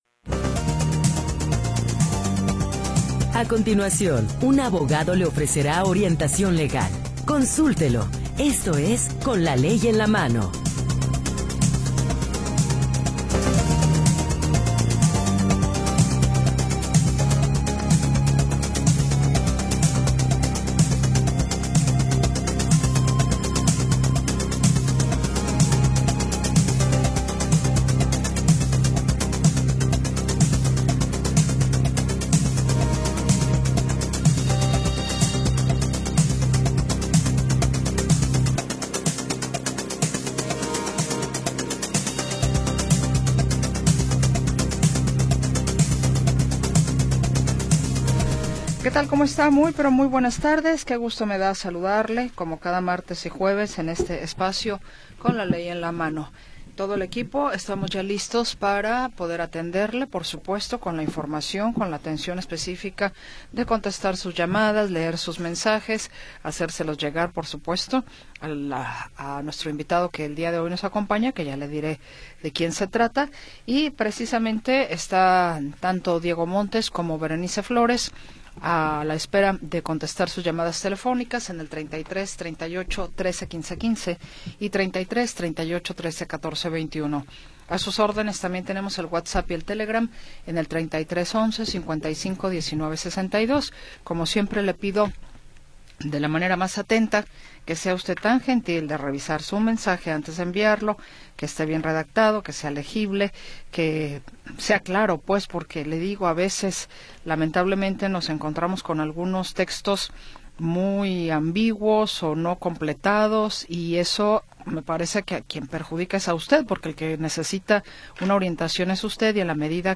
29 de Noviembre de 2025 audio Noticias y entrevistas sobre sucesos del momento